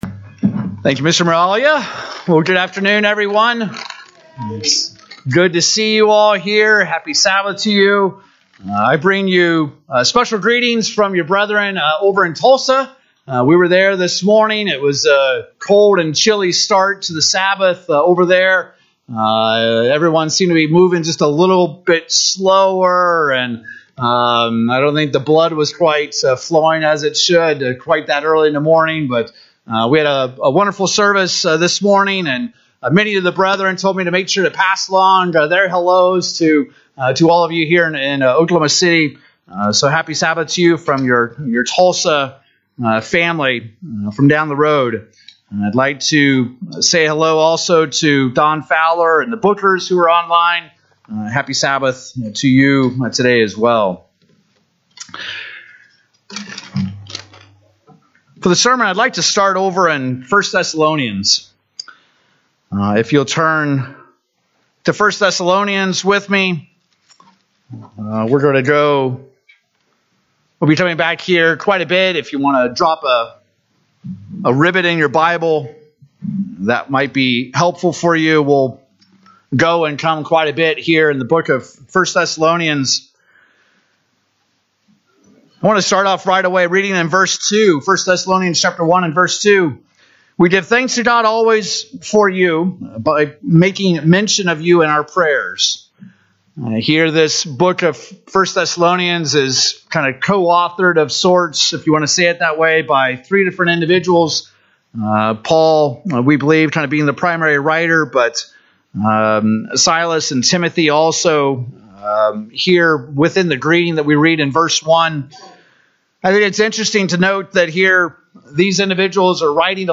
Today, how do you know if God is truly calling you? In this sermon we will look at the two components to every calling from God and where our focus should be in answering that call.
Given in Oklahoma City, OK Tulsa, OK